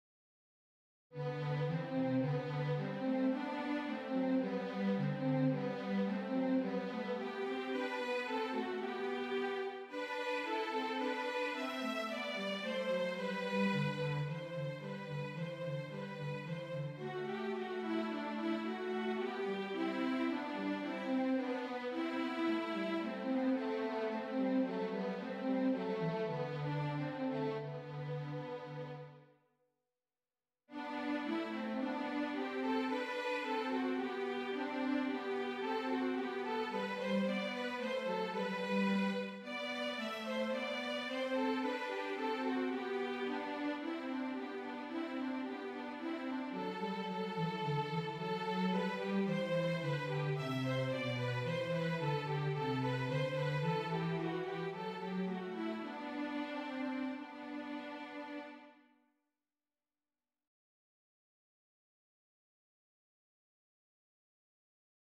Harmonies are consistent with piano accompaniments.